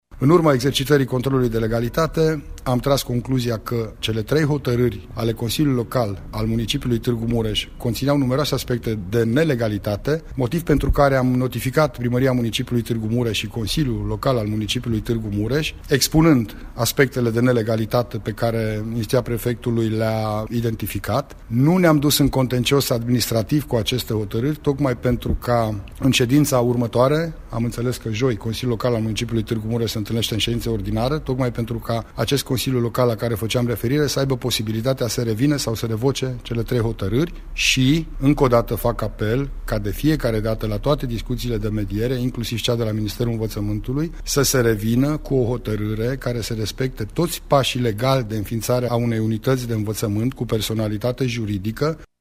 Prefectul Lucian Goga: